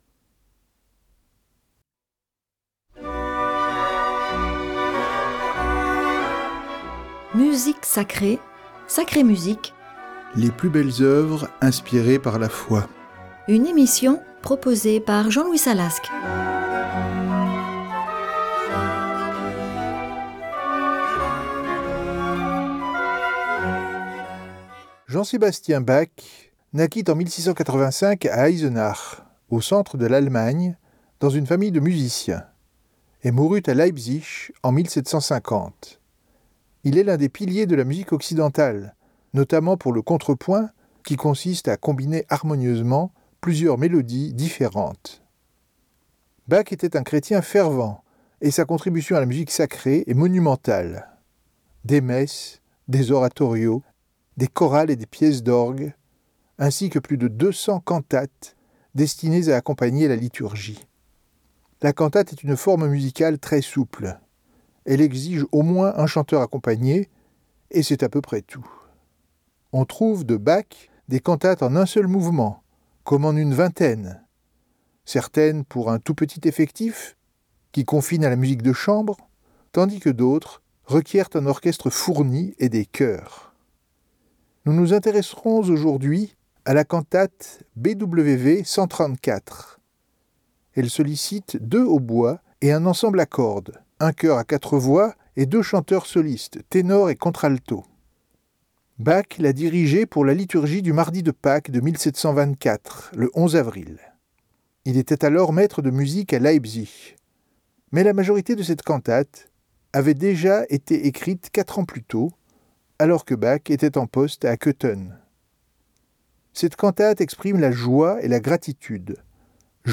Cette cantate recèle un merveilleux duetto entre contralto et ténor.